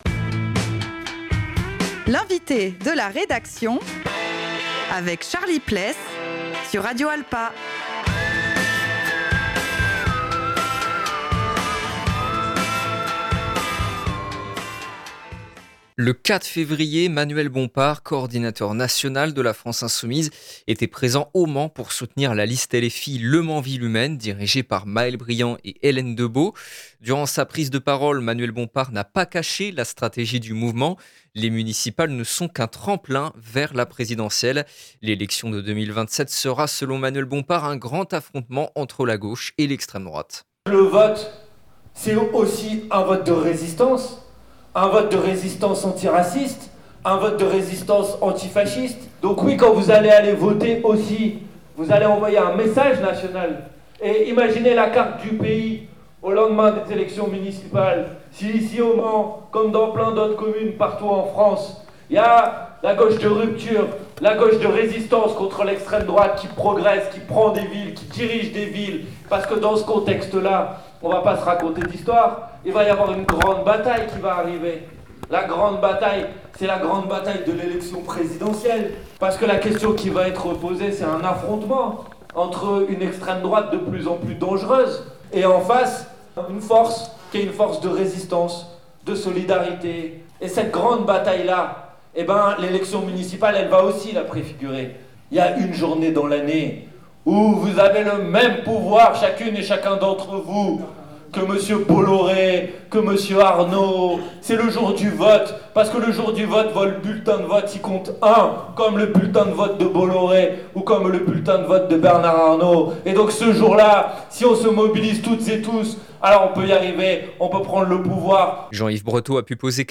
Durant sa prise de parole, Manuel Bompard n’a pas caché la stratégie du mouvement : les municipales ne sont qu’un tremplin vers la présidentielle.